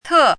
汉字“忑”的拼音是：tè。
忑的拼音与读音
tè.mp3